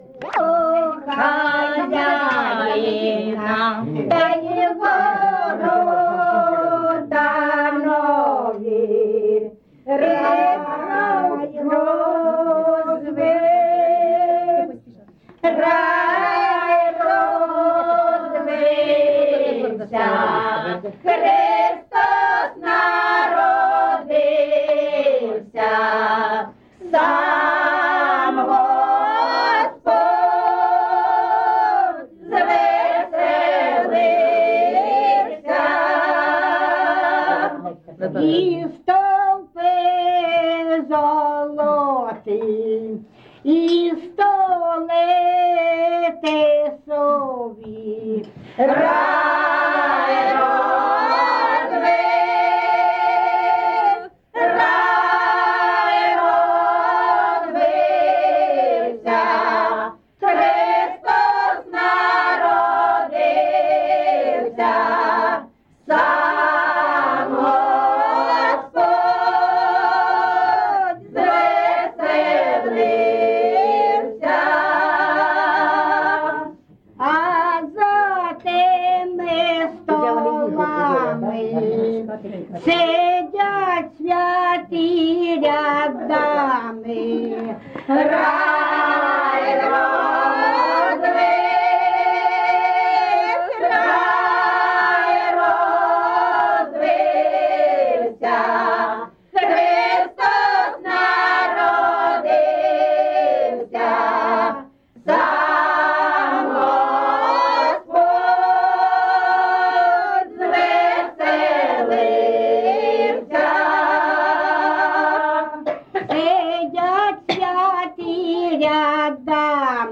ЖанрКолядки
Місце записус. Шарівка, Валківський район, Харківська обл., Україна, Слобожанщина